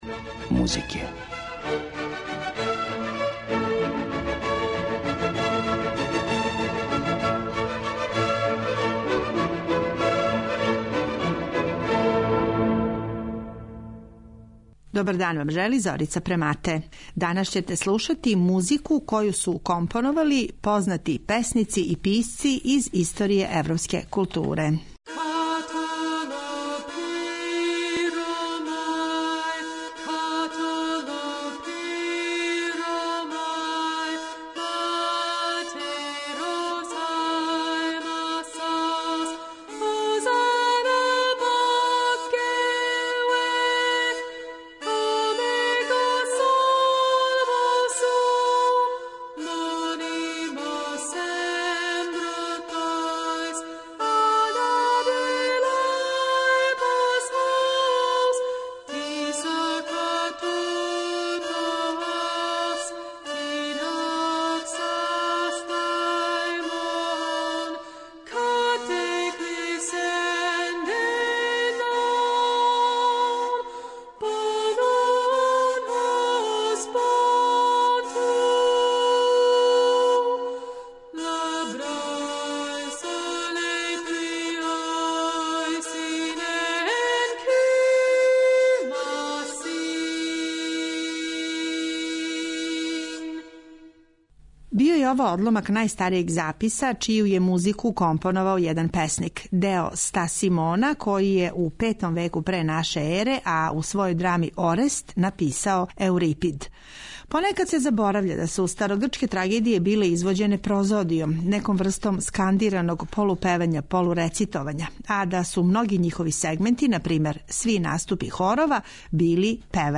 Подсетићемо се како је започео живот музике на сцени, у оквиру старогрчке трагедије и из пера Есхила, Софокла и Еурипида, а чућете и како звуче остварења средњовековних песника Бернадра де Вентадорна и Адама де ла Ала, као и соло-песме Фредерика Гарсије Лорке и оркестарска музика Џејмса Џојса. На крају, емитоваћемо и три хора нашег песника Алексе Шантића.